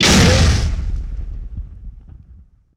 punch2.wav